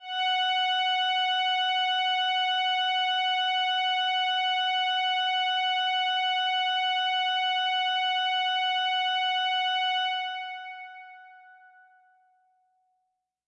描述：通过Modular Sample从模拟合成器采样的单音。
Tag: F6 MIDI音符-90 罗兰木星-4 合成器 单票据 多重采样